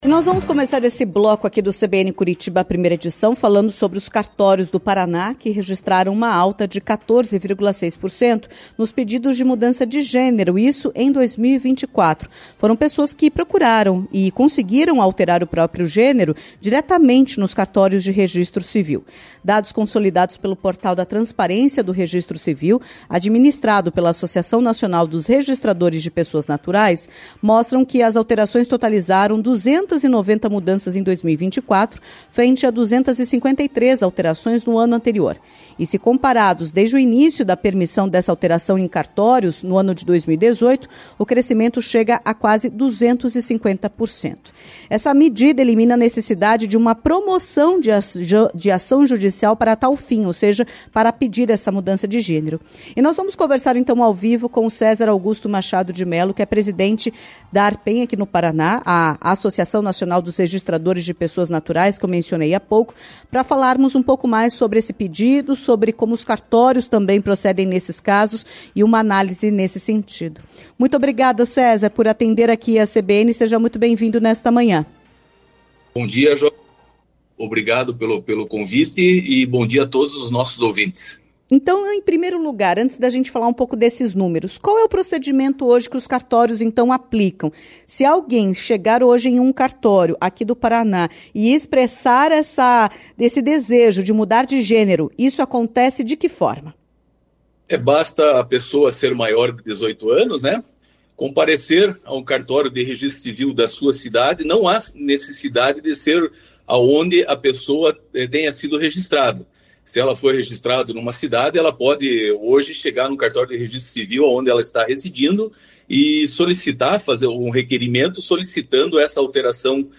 Em entrevista à CBN Curitiba